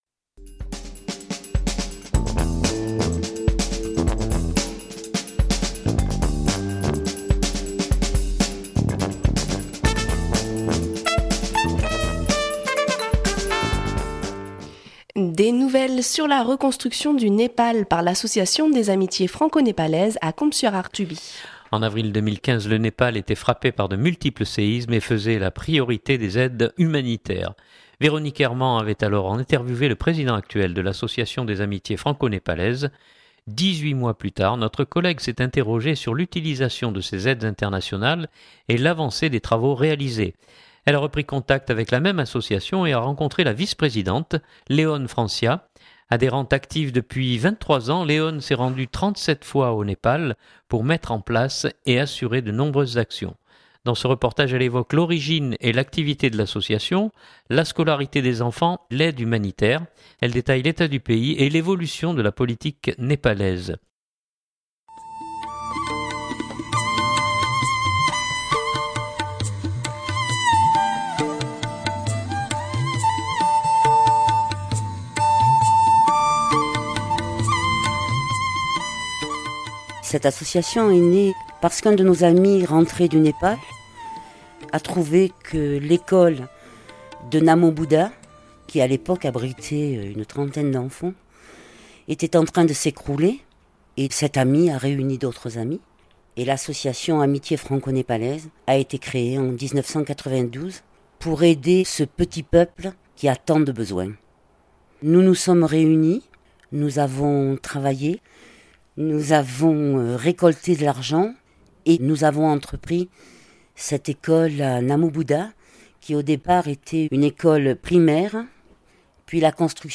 Dans ce reportage, elle évoque l’origine et l’activité de l’association, la scolarité des enfants, l’aide humanitaire, elle détaille l’état du pays et l’évolution de la politique Népalaise.